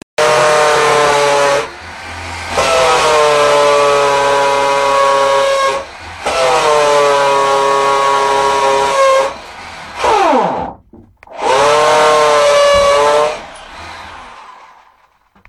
Звуки дрели, перфоратора
Звук прерывистого сверления дрелью